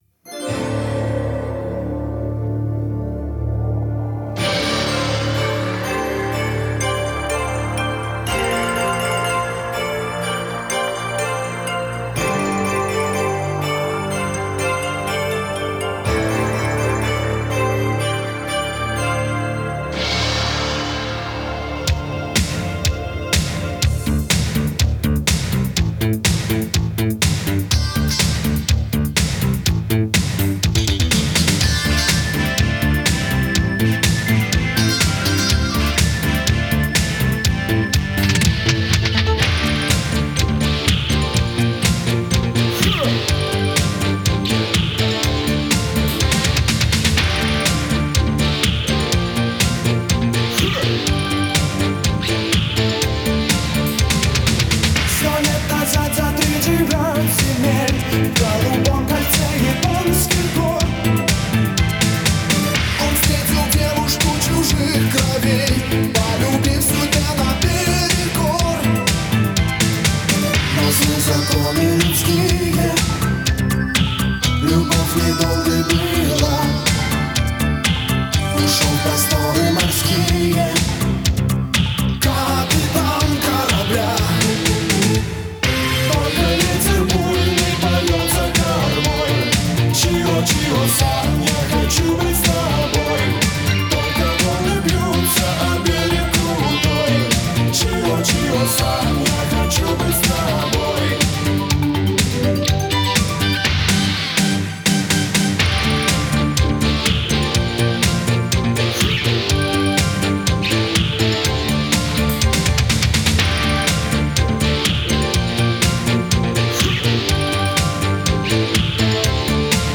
Музыкальный жанр: поп, ретро (диско 80-х)